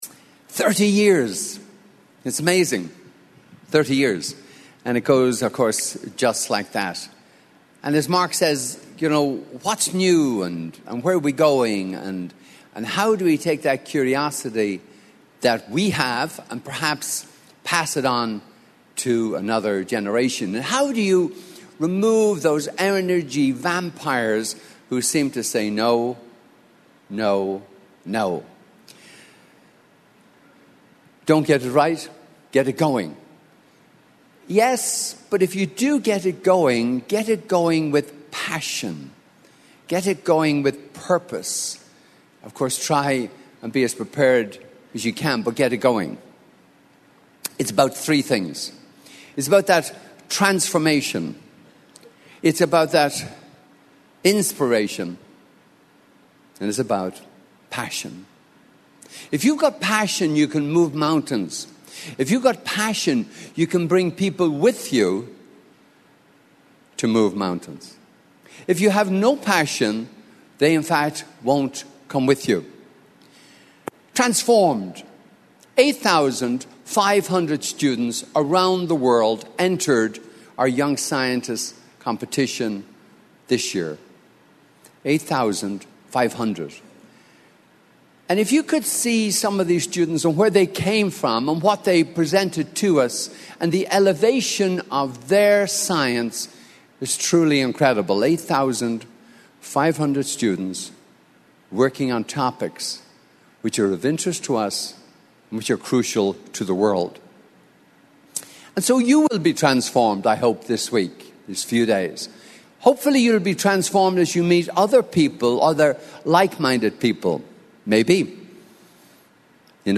Welcoming attendees from the stage is Dr. Pearse Lyons.